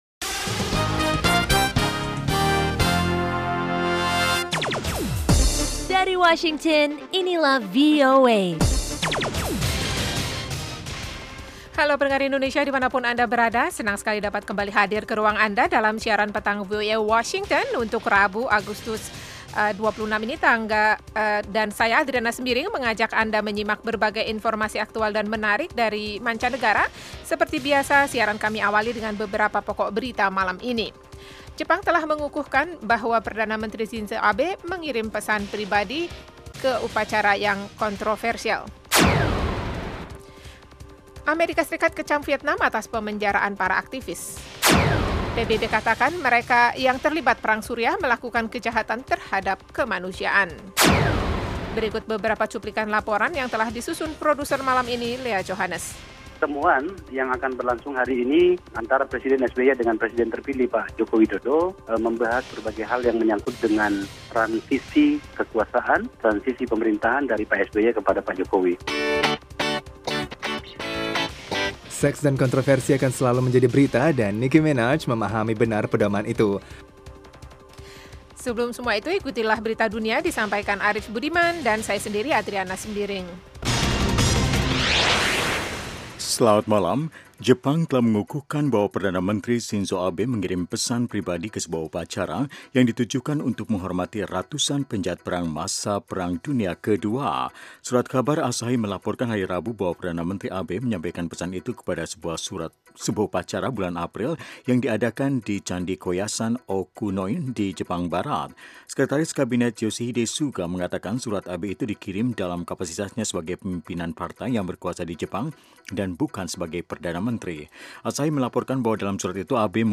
Selain padat dengan informasi, program ini sepanjang minggu menyuguhkan acara yang bernuansa interaktif dan penuh hiburan.
Kami menyajikan berbagai liputan termasuk mengenai politik, ekonomi, pendidikan, sains dan teknologi, Islam dan seputar Amerika. Ada pula acara musik lewat suguhan Top Hits, music jazz dan country.